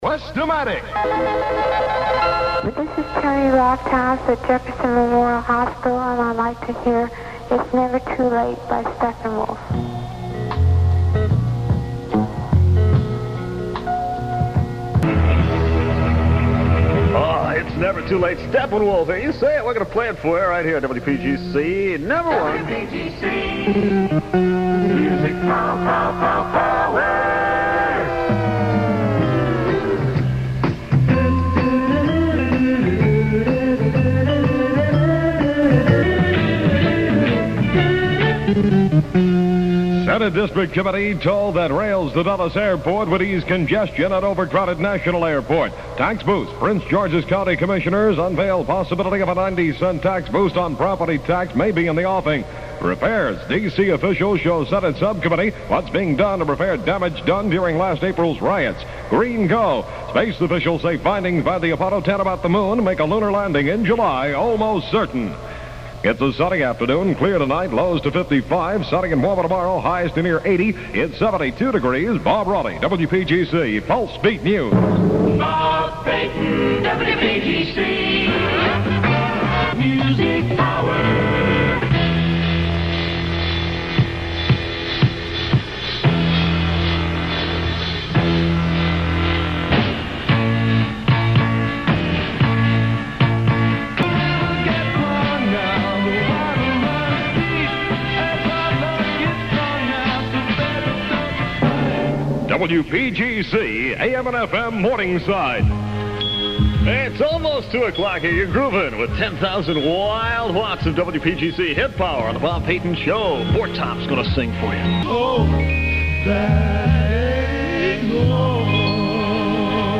Jingles in use came from PAMS' package, 'Grid'. Primary station positioners included 'WPGC #1' and 'Good Guys Radio'.